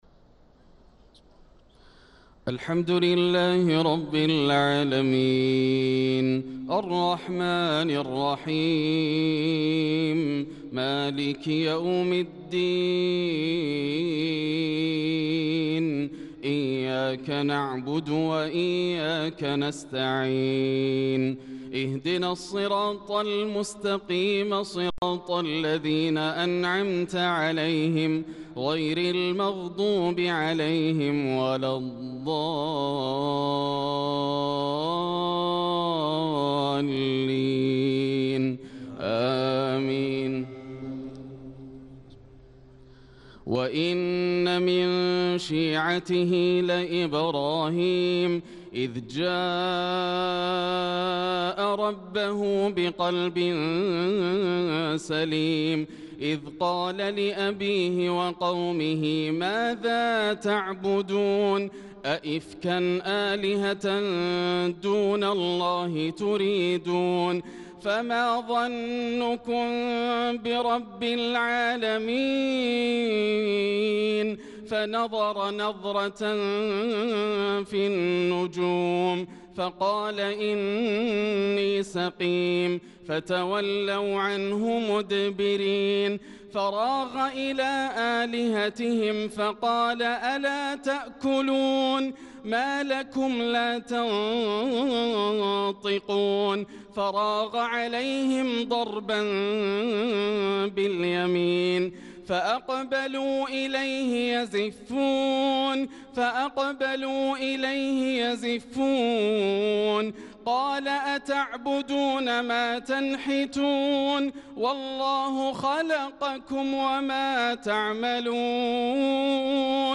صلاة الفجر للقارئ ياسر الدوسري 10 ذو الحجة 1445 هـ
تِلَاوَات الْحَرَمَيْن .